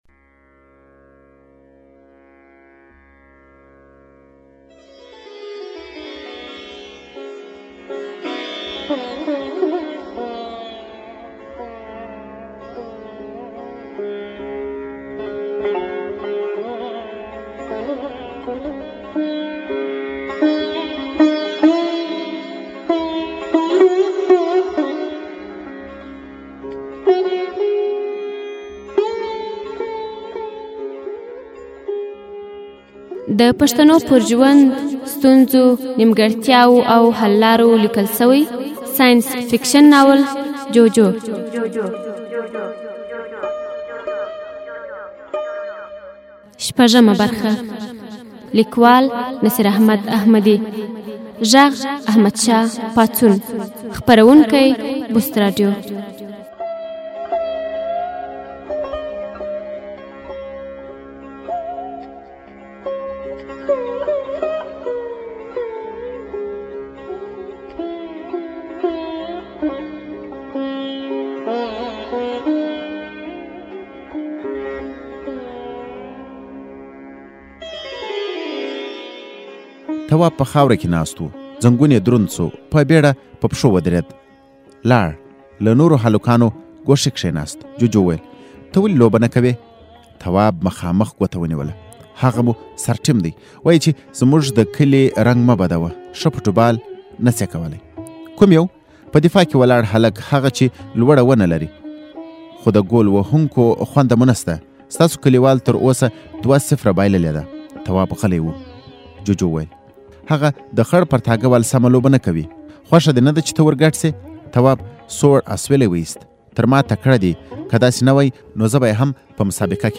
ږغیز ناولونه